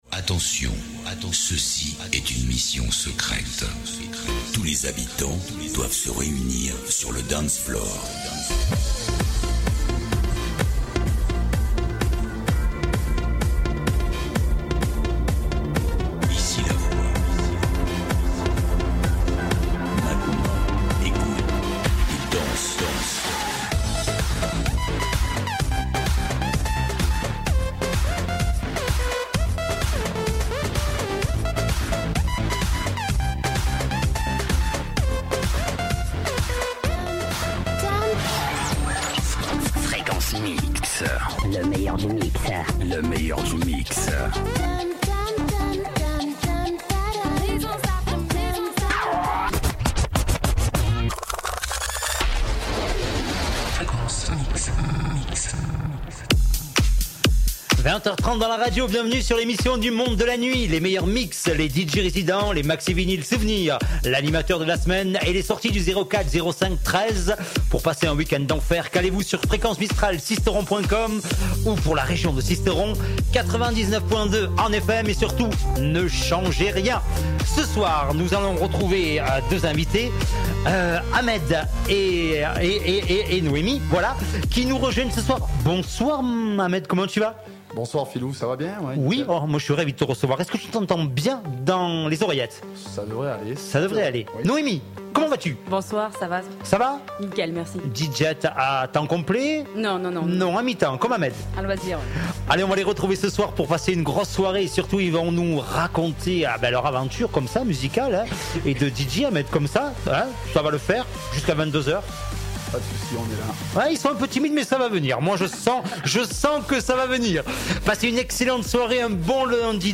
Des DJ's du monde entier livrent leurs mix's dans cette mouture unique en son genre. C'est le tour du monde des Night Club's !!!!!